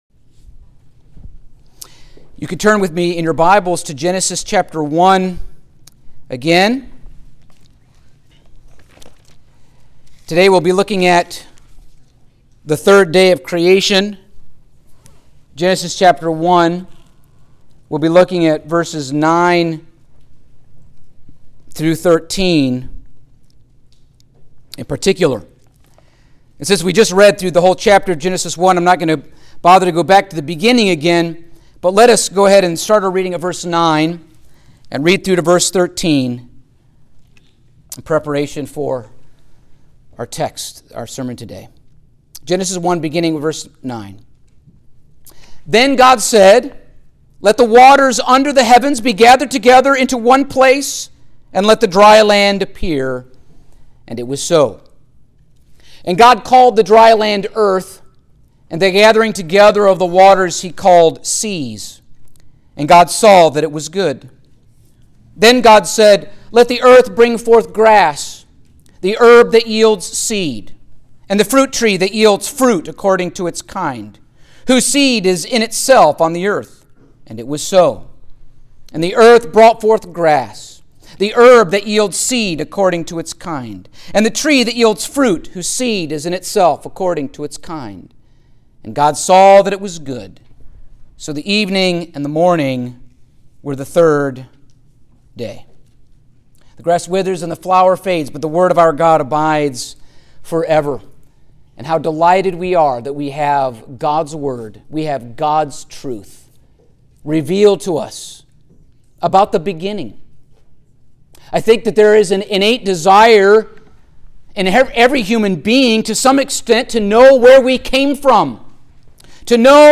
Genesis Passage: Genesis 1:9-13 Service Type: Sunday Morning Topics